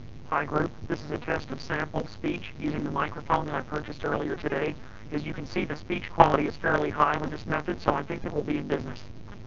'Voiced' frames, on the other hand, are excited with a periodic pulse train with a pulse rate corresponding to the fundamental frequency of the frame.
If you play the sound file below it is clear that the quality of the speech is much higher then with just the white noise.
Pitch Excited Speech